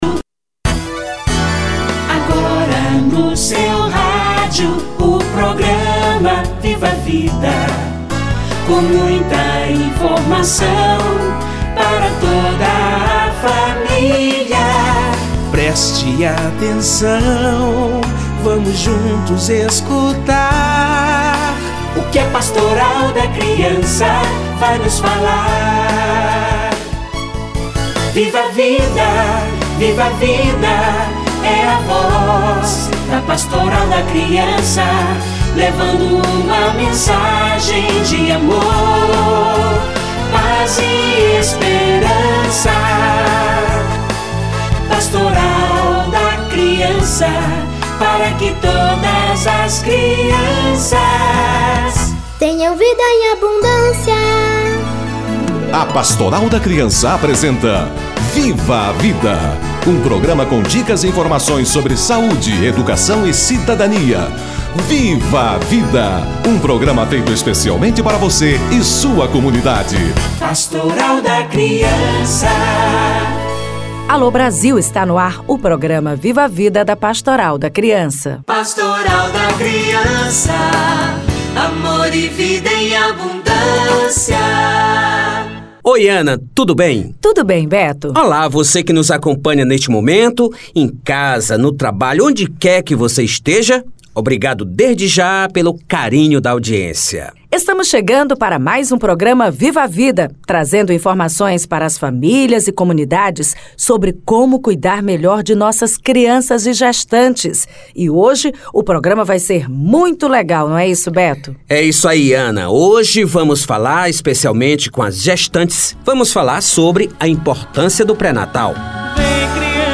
Importância do pré-natal - Entrevista